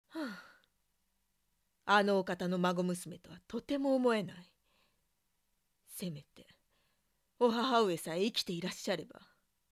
【サンプルボイス】